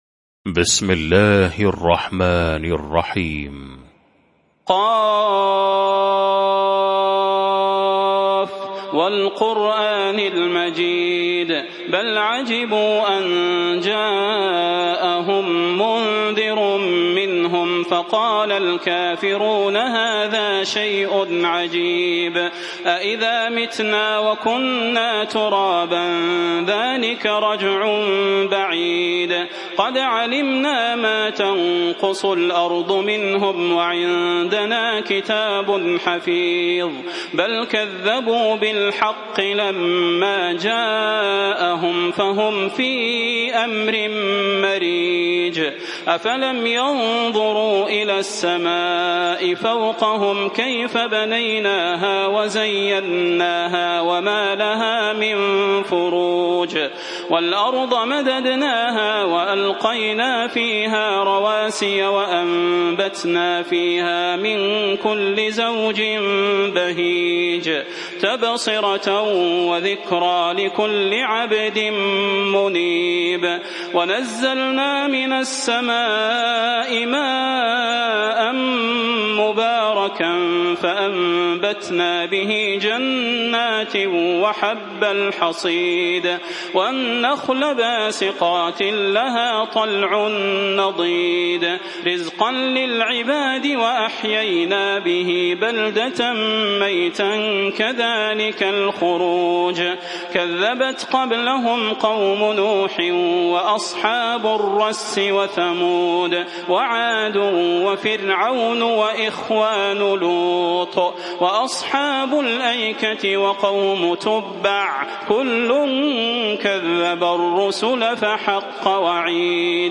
المكان: المسجد النبوي الشيخ: فضيلة الشيخ د. صلاح بن محمد البدير فضيلة الشيخ د. صلاح بن محمد البدير ق